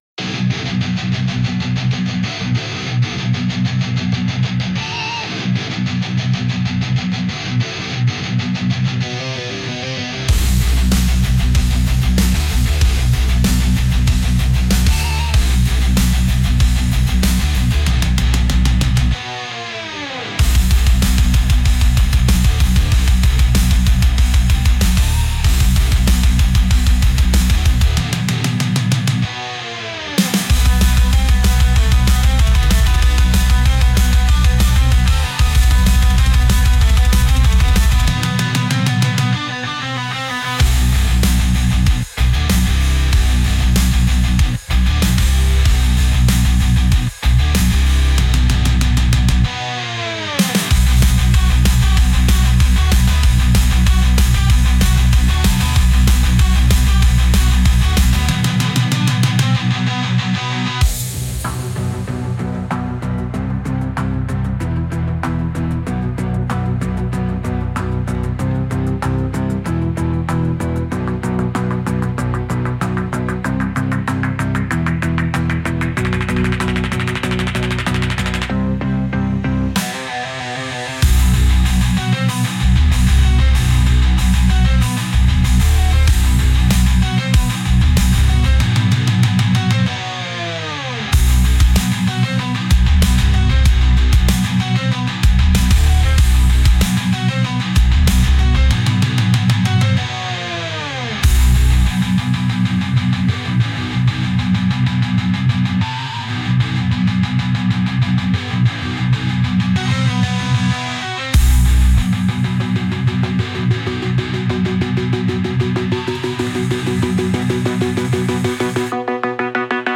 Genre: Metal Mood: Aggressive Editor's Choice